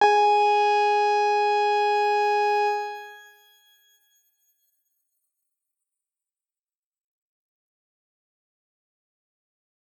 X_Grain-G#4-pp.wav